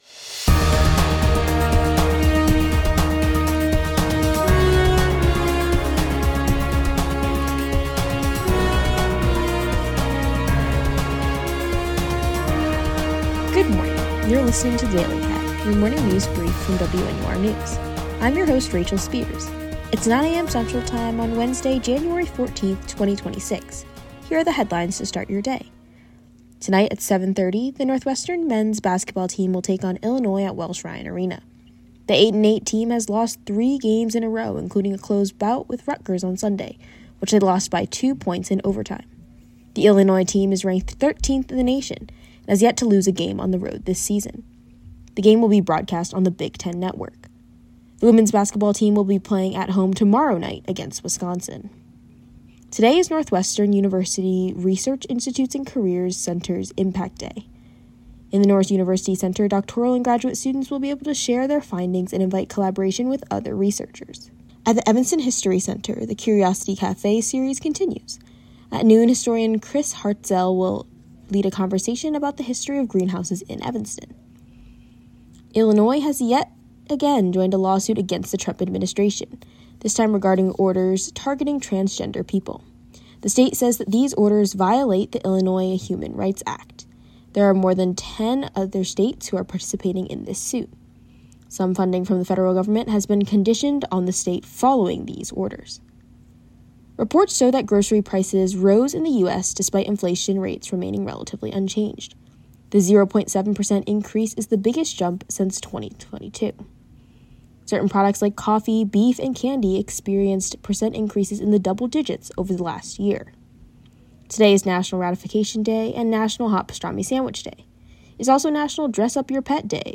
January 14, 2026: Northwestern Basketball, URIC Research Impact Day, Illinois lawsuit, inflation. WNUR News broadcasts live at 6 pm CST on Mondays, Wednesdays, and Fridays on WNUR 89.3 FM.